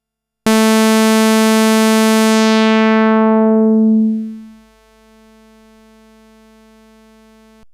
Im Verlauf dieses langen Threads ist zweimal das Thema "leckender Filter" aufgekommen: Bei geschlossenem Tiefpassfilter ist leise das Originalsignal zu hören.
Im Anhang zur Illustration eine Frequenzanalyse mit einem Sägezahn bei offenem und geschlossenem Tiefpass, sowie ein Link zum dazugehörenden Audiofile: Filtersweep von offen nach zu, Resonanzregler auf 0.
tinysizer-LPsweep10bis0.wav